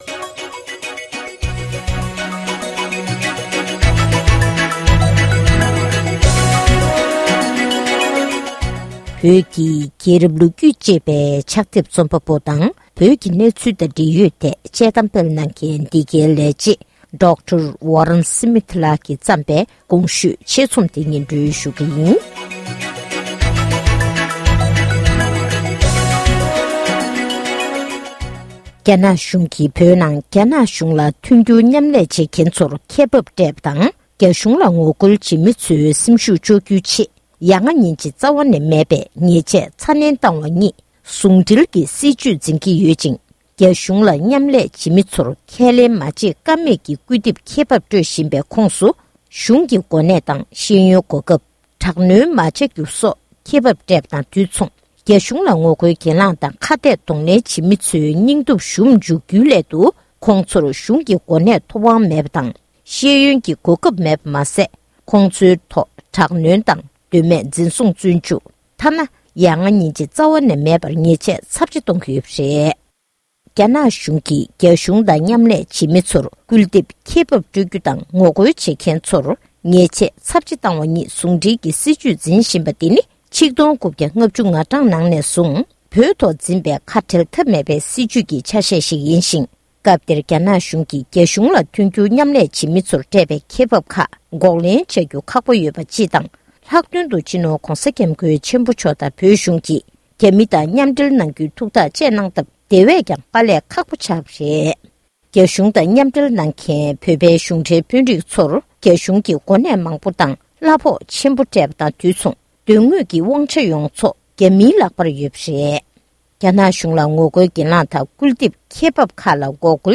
ཕབ་བསྒྱུར་གྱིས་སྙན་སྒྲོན་ཞུས་པར་གསན་རོགས༎